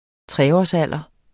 Udtale [ ˈtʁεɒs- ]